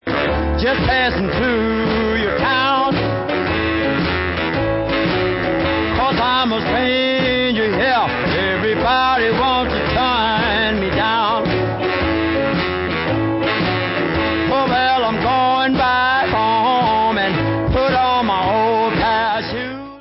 The best r&b guitarist in the world!? he did it all!!